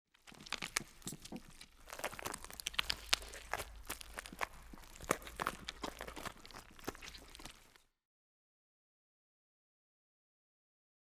burer_eat_1.ogg